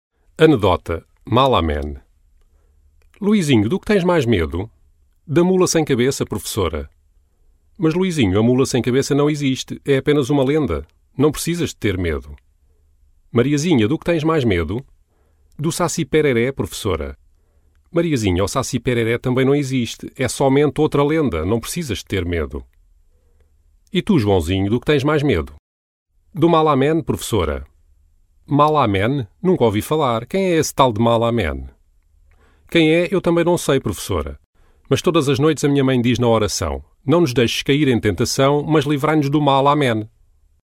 Ana dil spikerleri